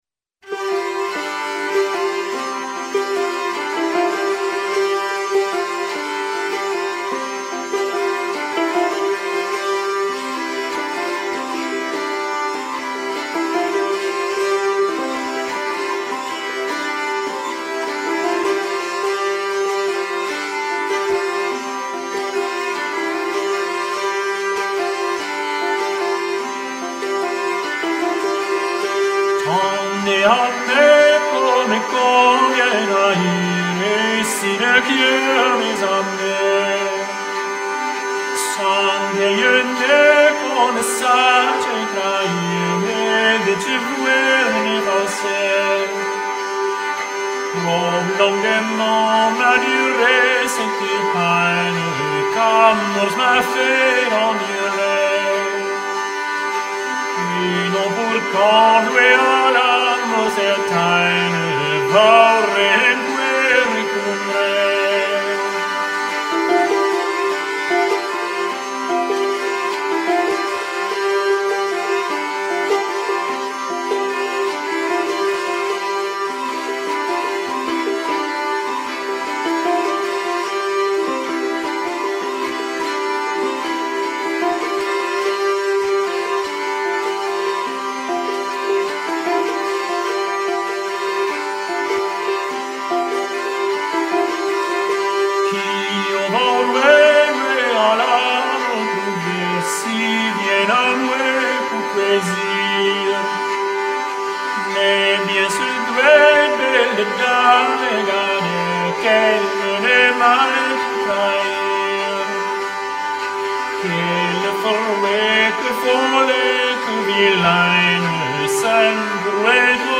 Vocal [89%] Choral [11%]